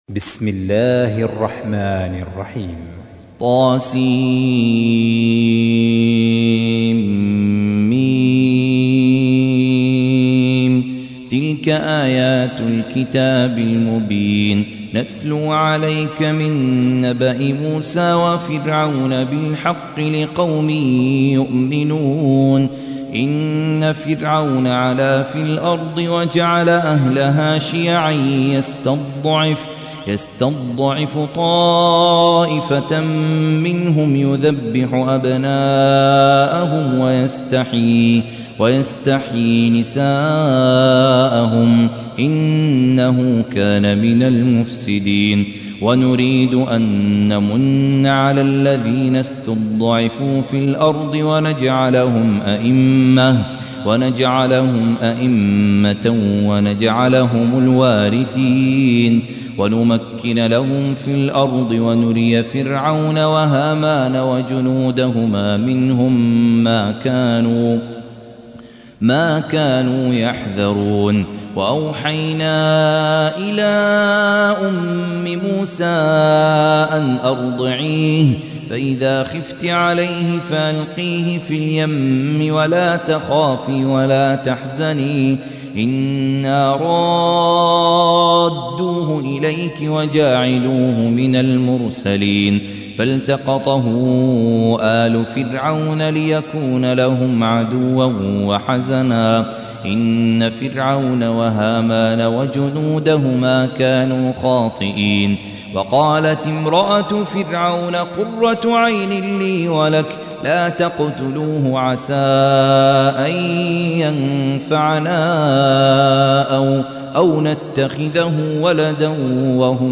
تحميل : 28. سورة القصص / القارئ خالد عبد الكافي / القرآن الكريم / موقع يا حسين